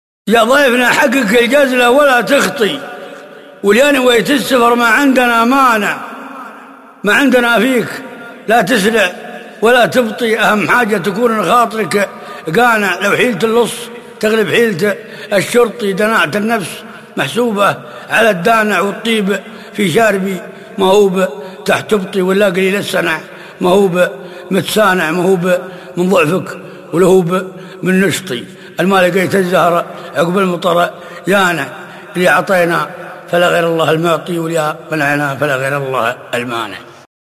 ياضيفنا - القاء سعد بن جدلان